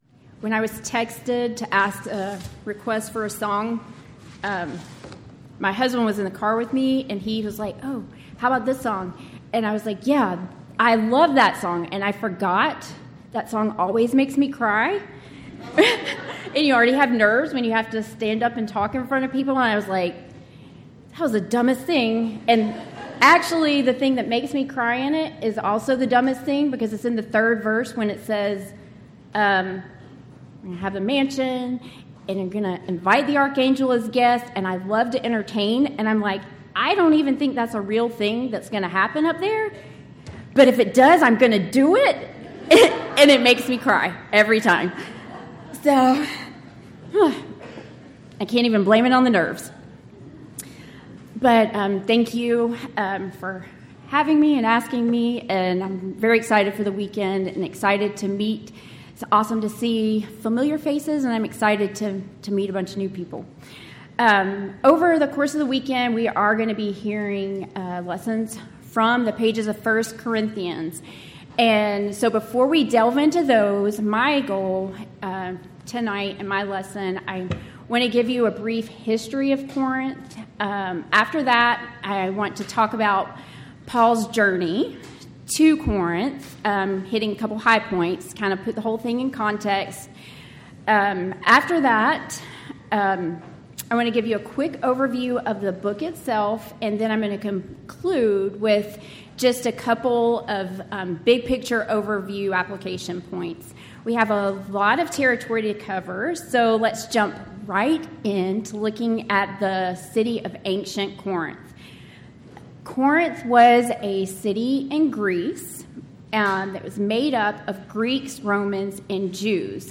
Event: 13th Annual Texas Ladies in Christ Retreat Theme/Title: Studies in 1 Corinthians
Ladies Sessions